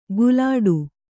♪ gulāḍu